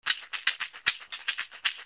ブルキナファソ製 カシシ
植物繊維の篭、底は瓢箪。小石が入っています。
カシシ小1個の音